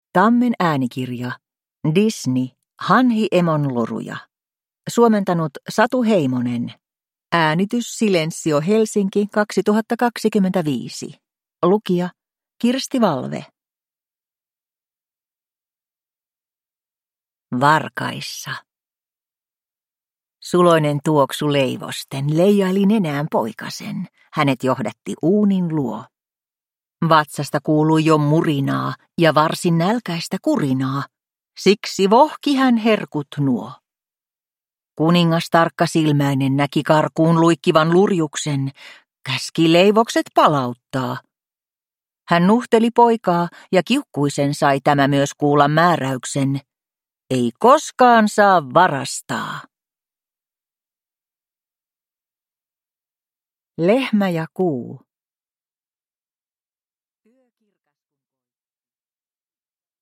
Disney. Hanhiemon loruja – Ljudbok
Ikiklassikot kohtaavat, kun Disneyn suosikkihahmot seikkailevat Hanhiemon runojen vastustamattoman poljennon tahtiin.